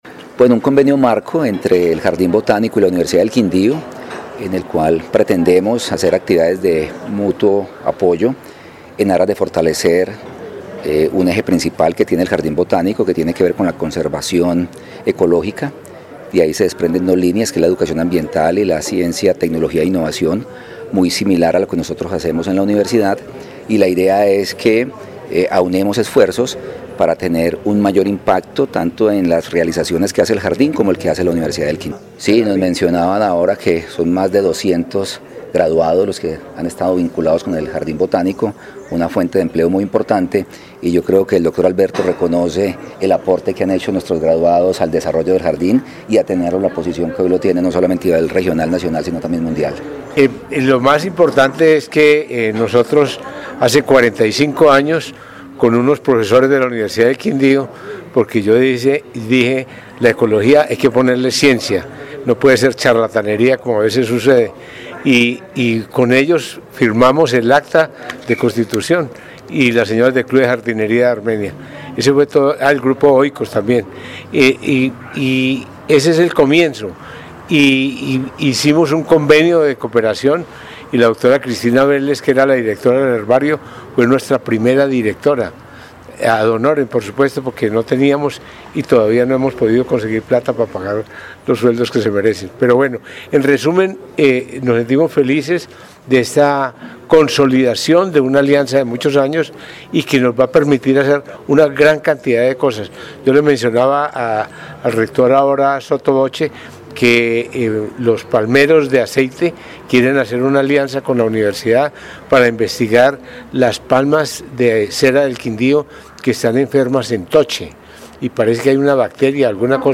Informe Jardín Botánico- Universidad del Quindío